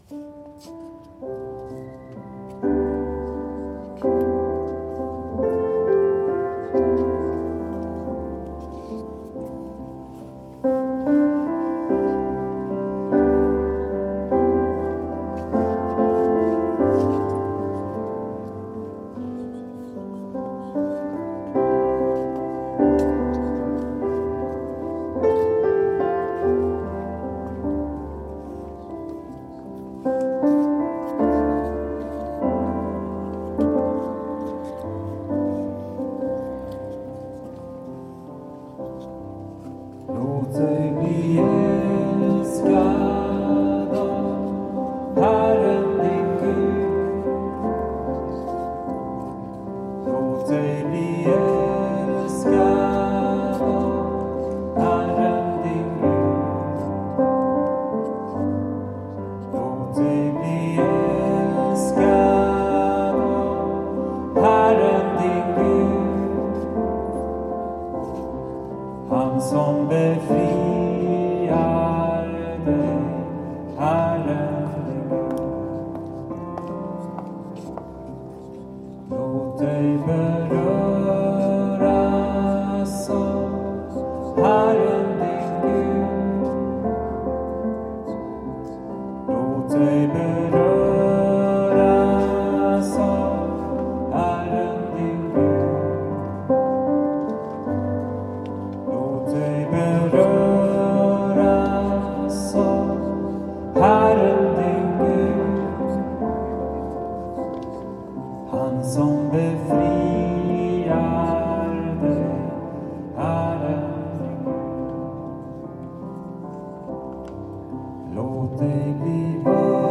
Inspelat i Uppsala domkyrka.
Sånger från Ekumenisk gudstjänst 23 nov 2014.